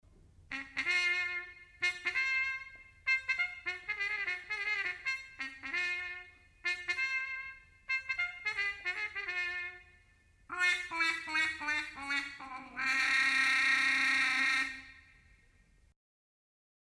Strumento in ottone che produce il suono grazie alla vibrazione provocata dalle labbra del musicista contro il bocchino a tazza.
La sonorità sfavillante dello strumento deriva dalla sua struttura che prevede il bocchino a forma di tazza, lo stretto tubo metallico, la campana svasata.
tromba con sordina
tromba_sordina.mp3